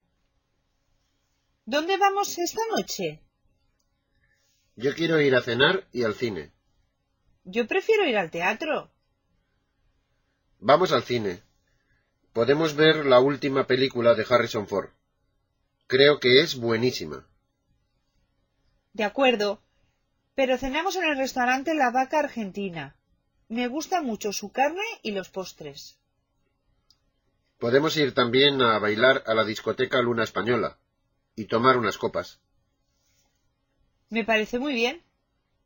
Listen to the dialogue of a couple who want to go out Saturday night.